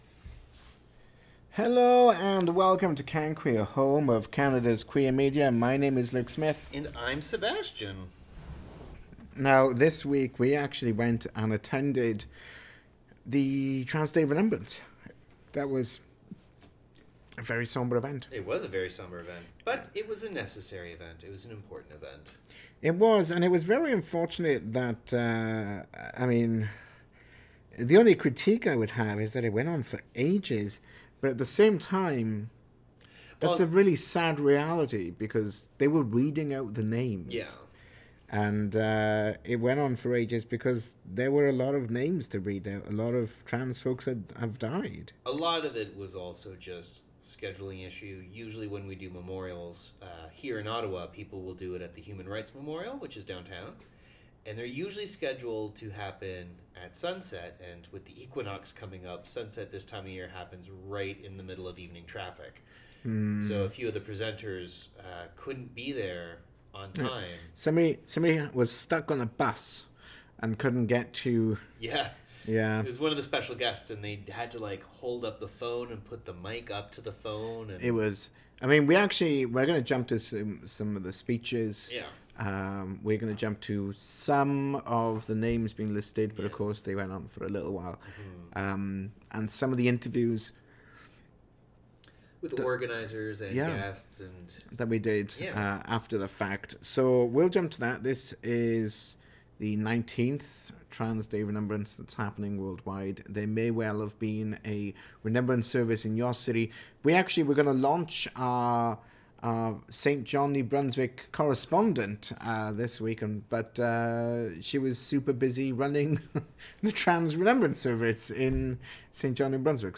The CanQueer team go to the local Trans Day of Remembrance